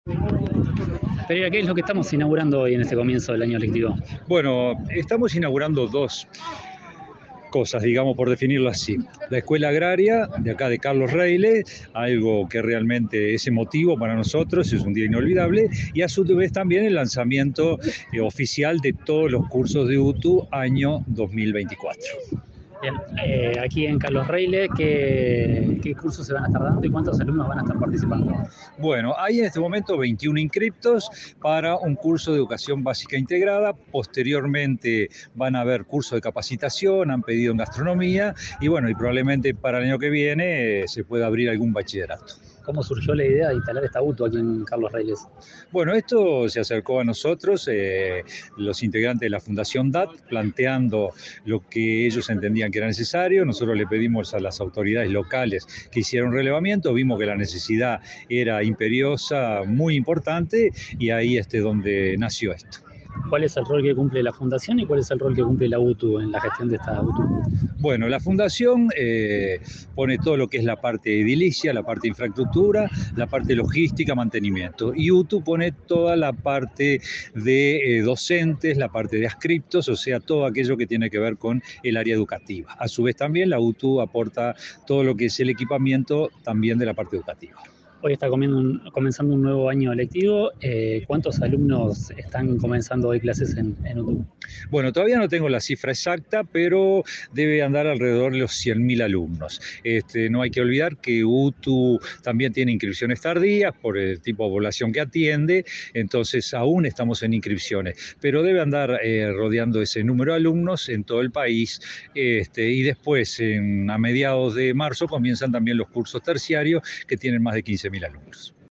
Entrevista al director general de Educación Técnica, Juan Pereyra